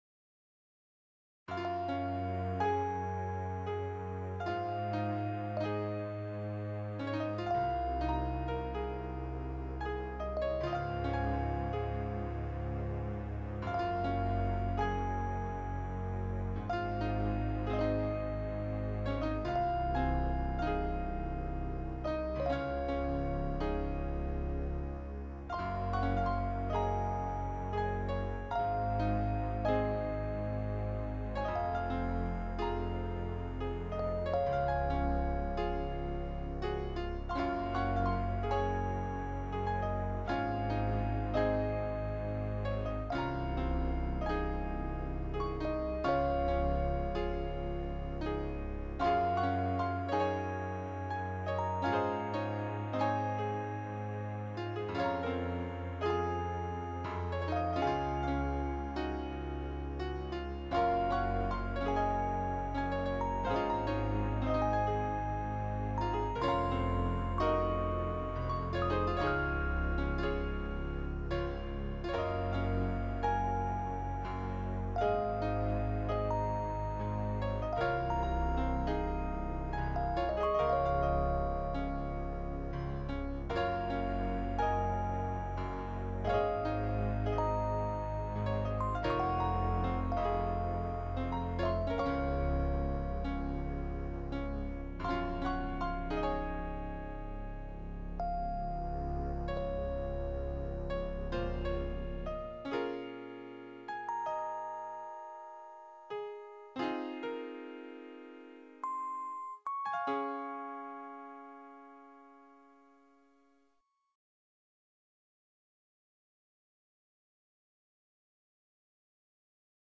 Piano Solo Tune I made for game, still needs fine tuining.